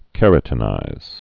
(kĕrə-tə-nīz)